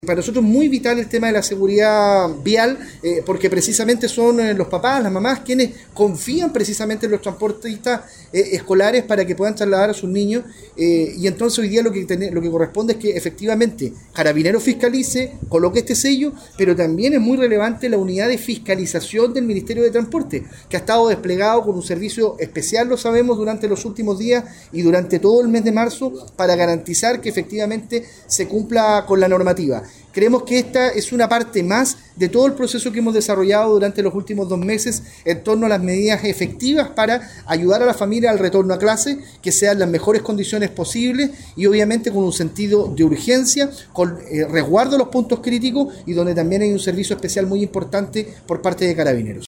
cuna-2-Delegado-eduardo-pacheco-fiscalizacion-transporte-escolar.mp3